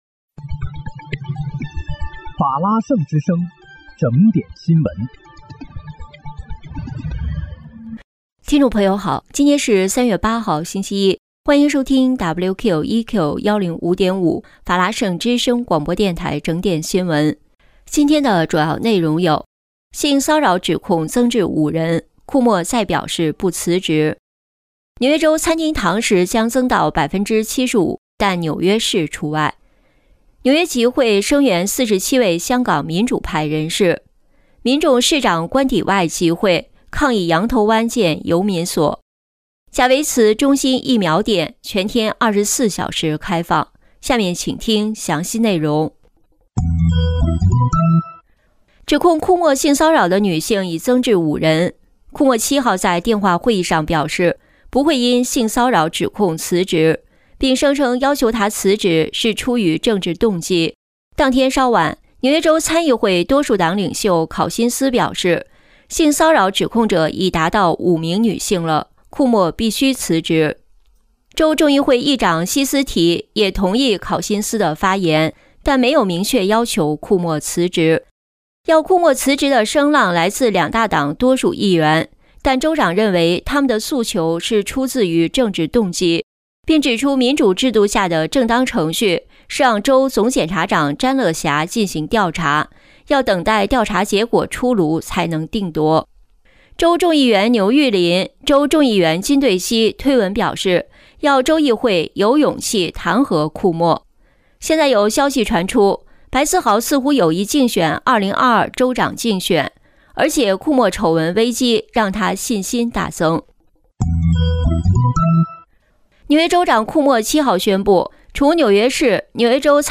3月8日（星期一）纽约整点新闻